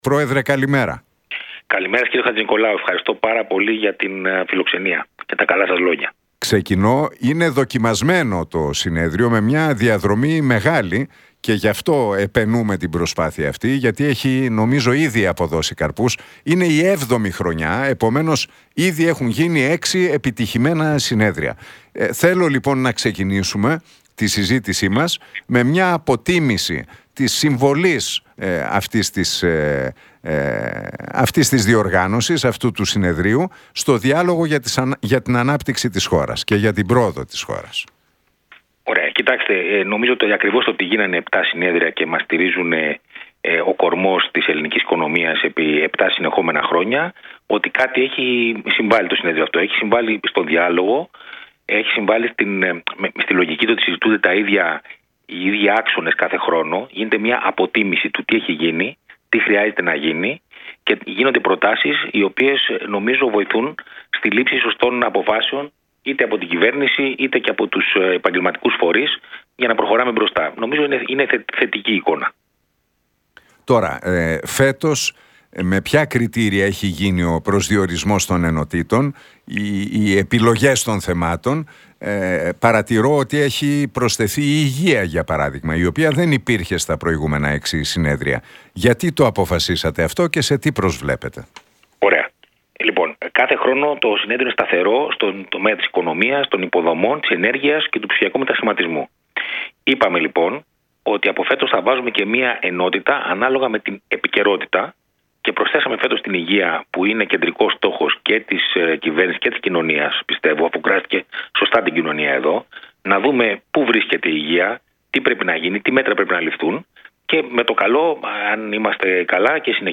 στον Νίκο Χατζηνικολάου από την συχνότητα του Realfm 97,8.